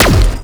Zapper_3p_01.wav